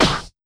130UKSNAR2-L.wav